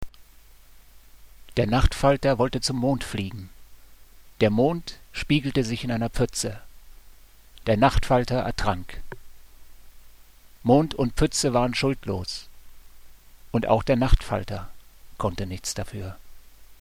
(Inwendig vorgetragen:)